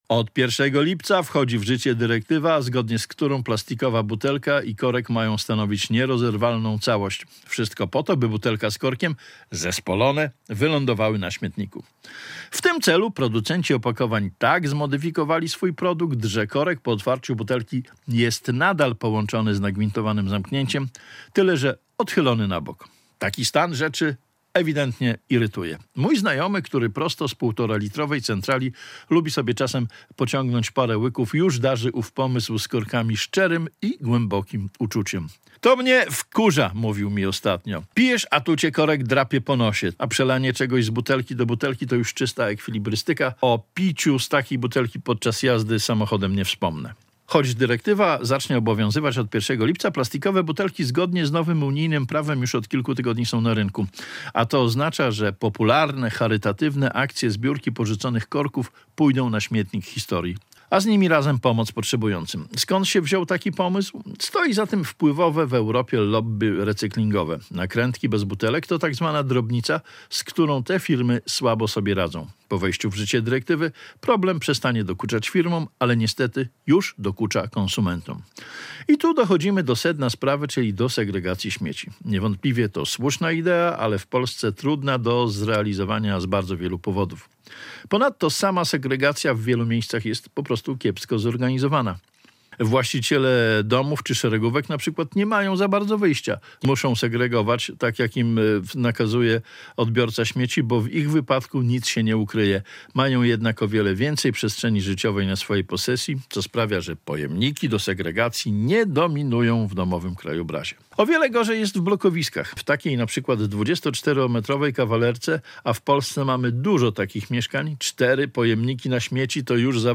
Dyrektywa butelkowa - felieton